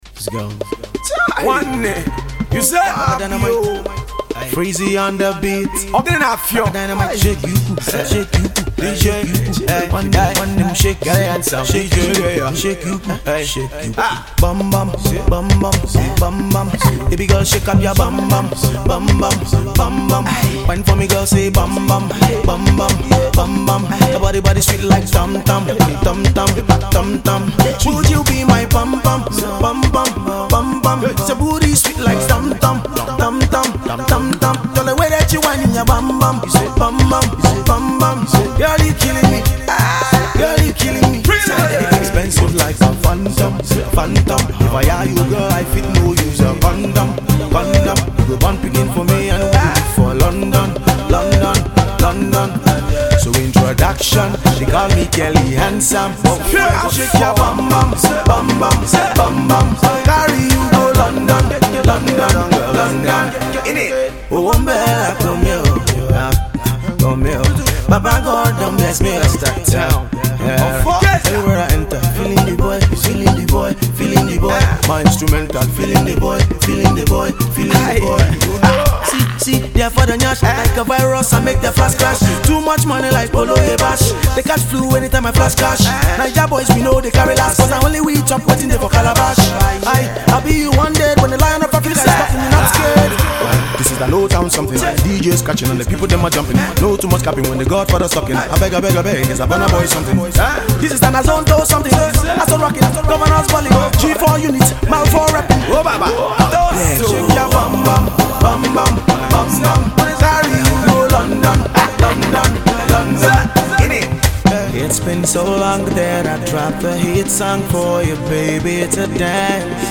freestyle
it gives off the right vibe for the clubs